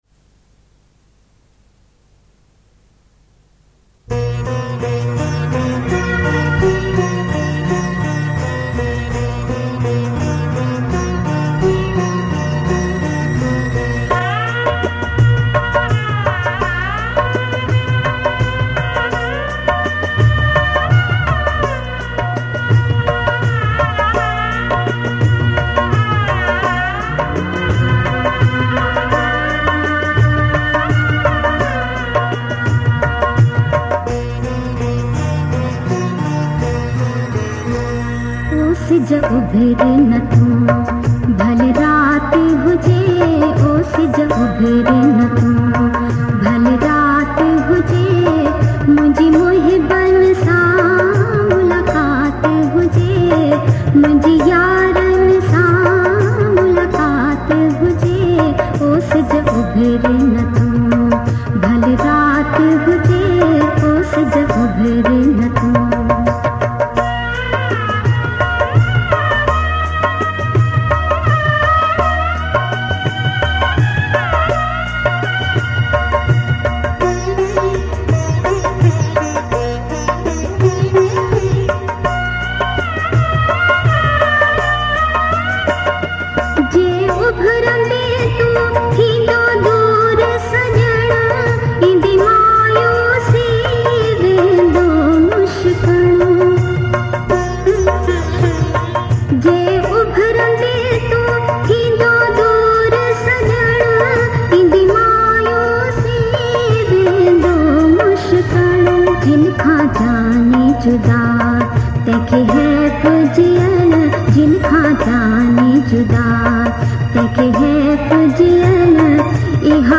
Sindhi Kalam, Geet, Qawali, Duet